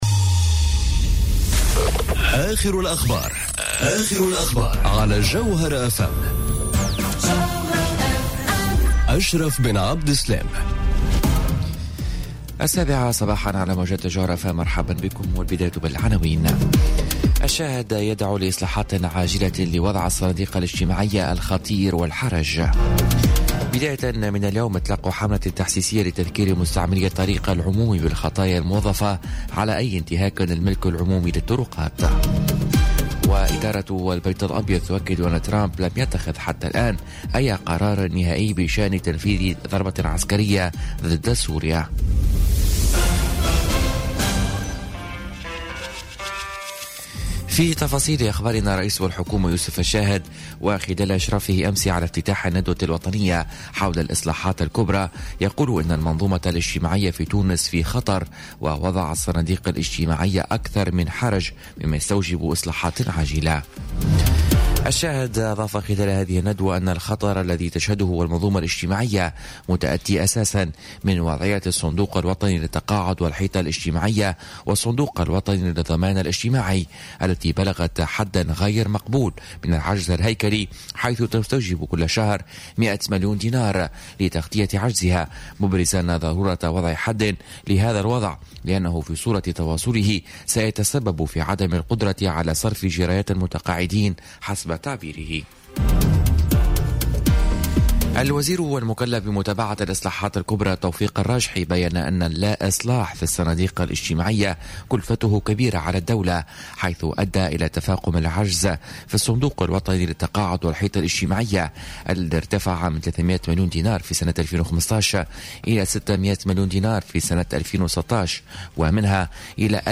نشرة أخبار السابعة صباحا ليوم الخميس 12 أفريل 2018